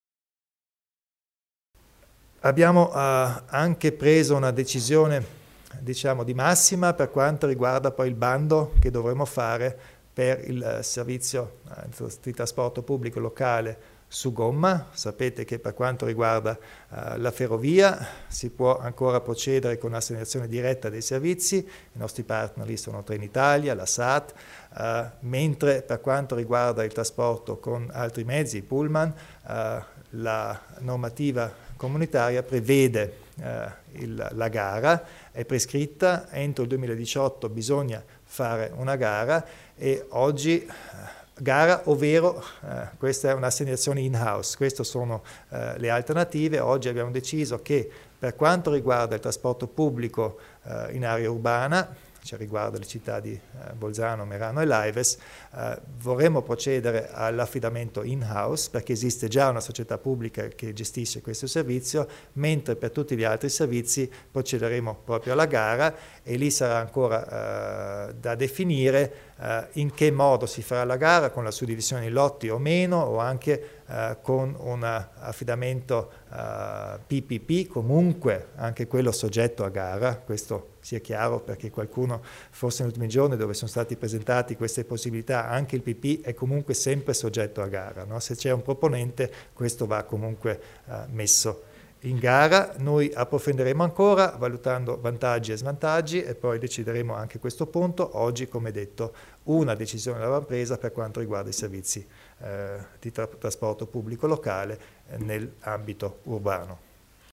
Il Presidente Kompatscher spiega le novità per il trasporto pubblico urbano